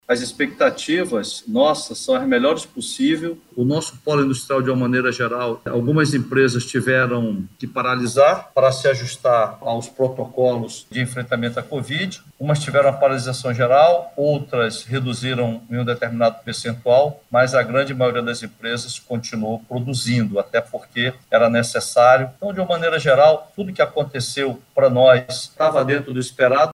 Alfredo Menezes, ainda assim, afirma que as expectativas para a retomada do setor industrial no âmbito da Suframa são as melhores possíveis, diante do cenário de crise mundial.